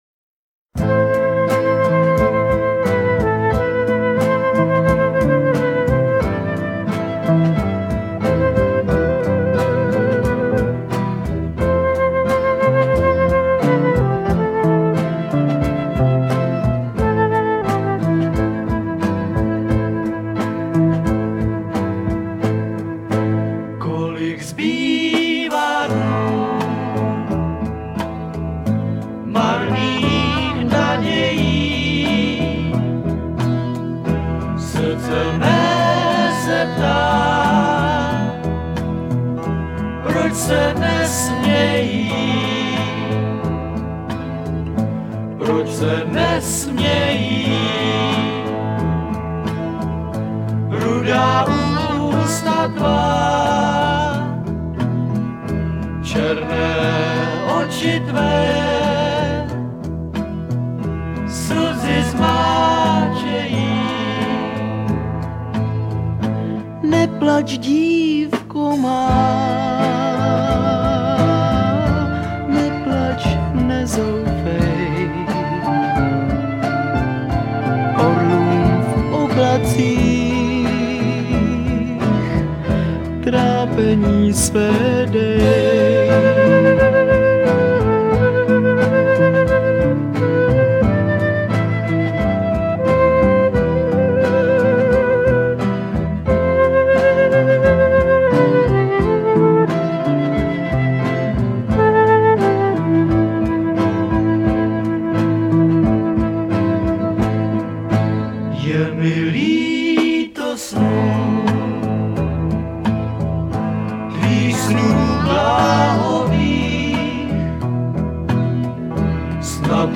Written-By [Arménská Lidová] – Folk
Vocal